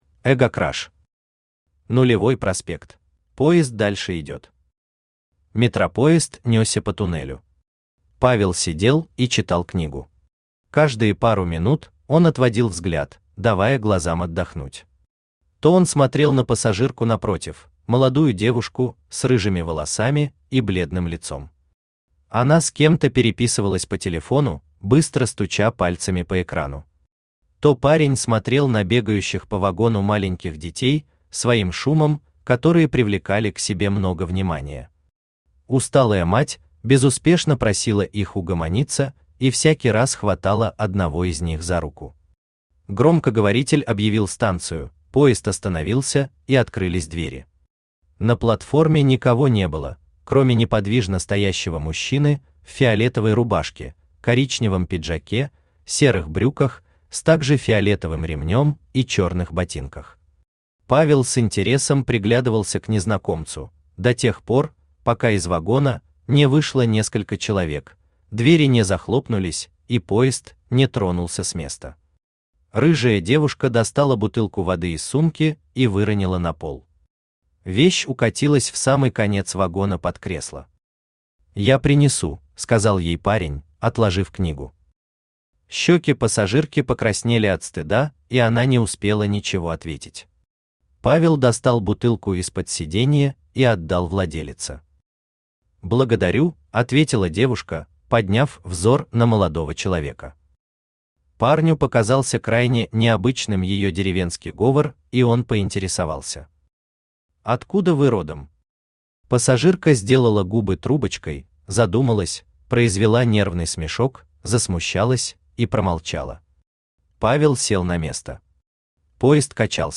Аудиокнига Нулевой Проспект | Библиотека аудиокниг
Aудиокнига Нулевой Проспект Автор EGOCRASH Читает аудиокнигу Авточтец ЛитРес.